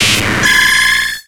Cri d'Octillery dans Pokémon X et Y.